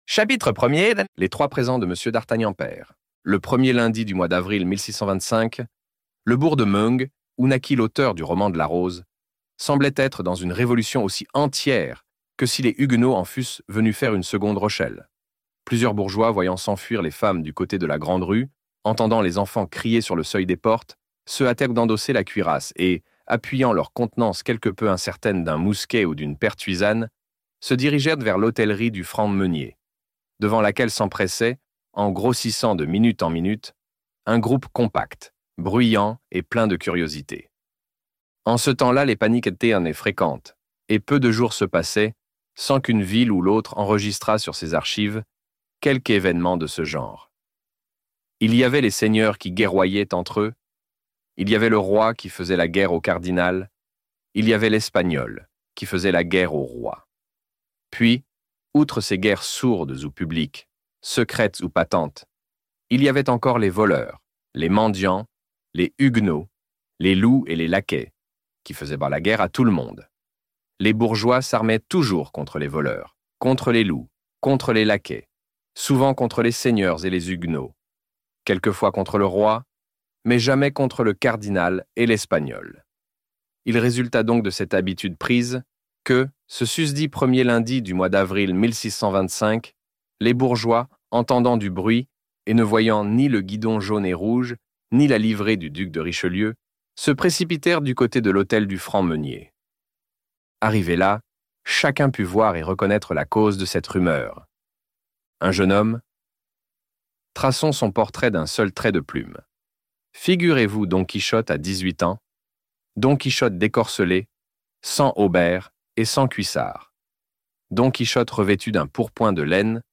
Les Trois Mousquetaires - Livre Audio